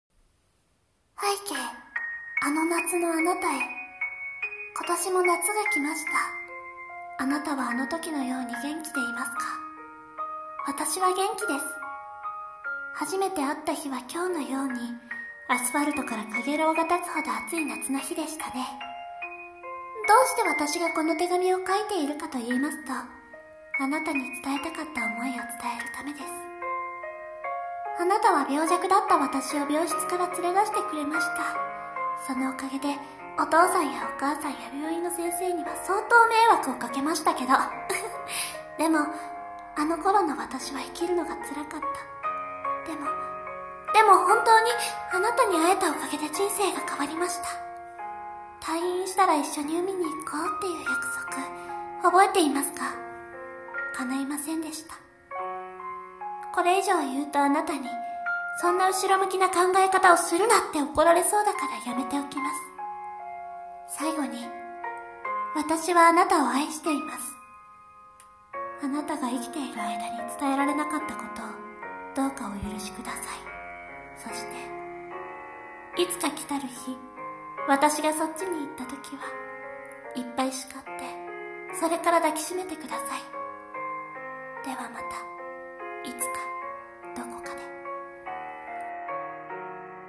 〜拝啓 あの夏の君へ〜 声劇 朗読